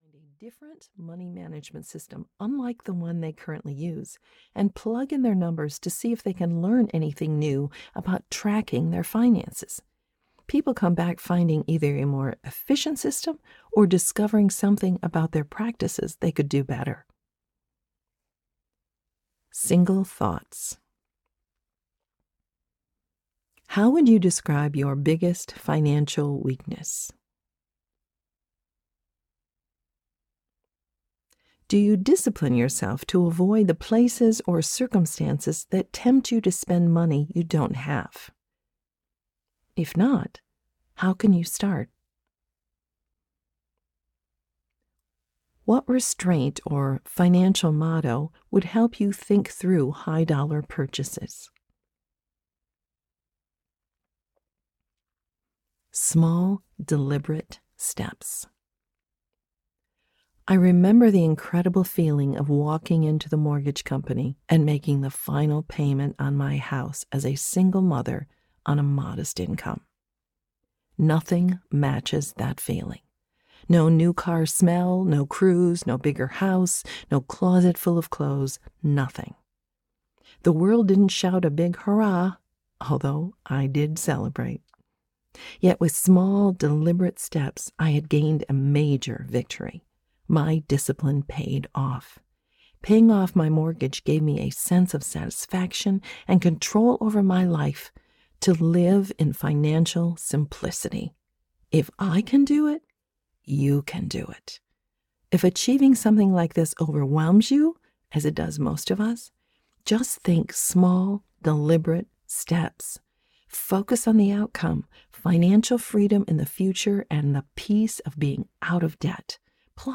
Suddenly Single Audiobook